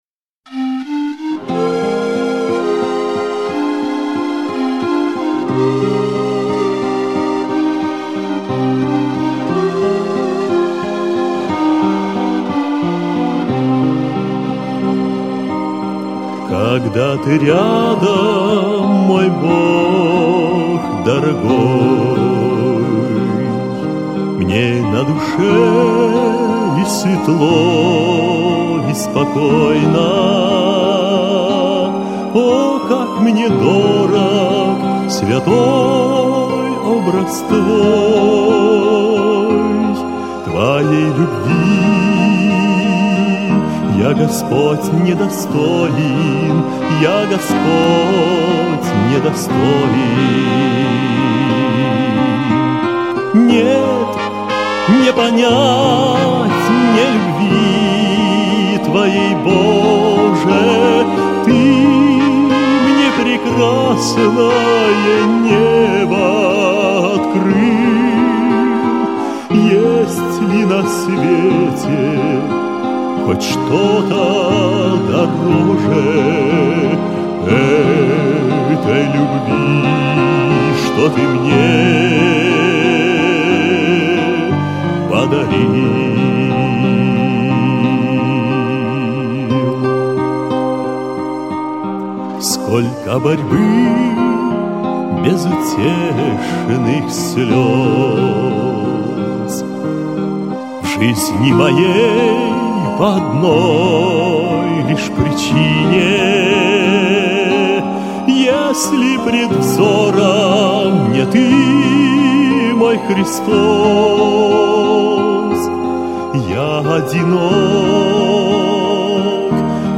395 просмотров 898 прослушиваний 43 скачивания BPM: 77